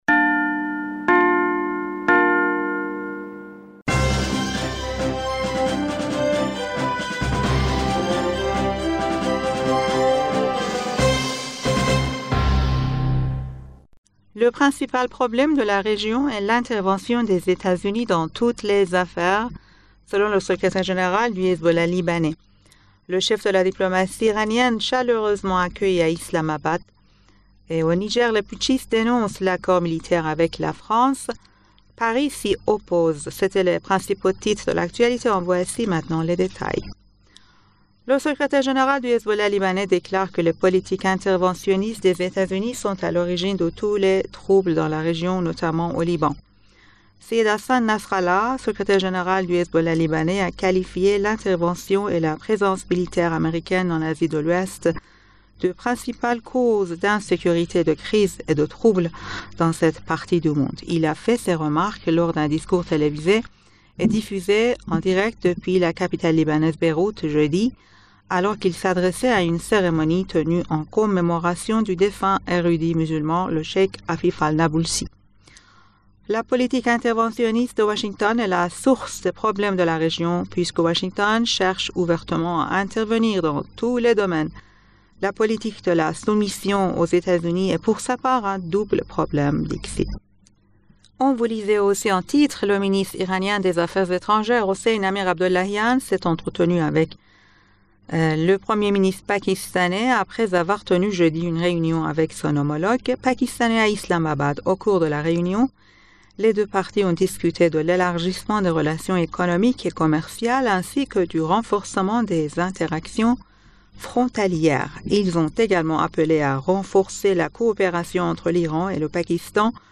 Bulletin d'information du 04 Aout 2023